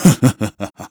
threefish_laugh.wav